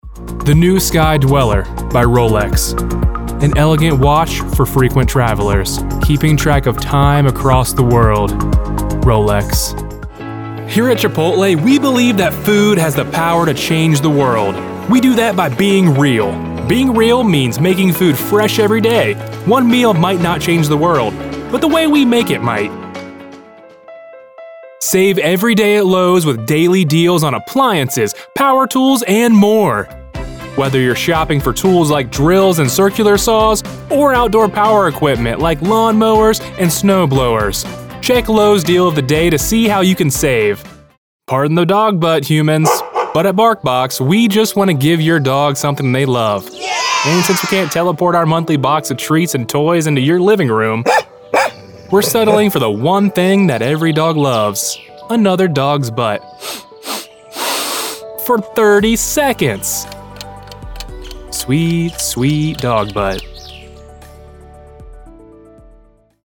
Male
Radio / TV Imaging
Commercial And Radio Ads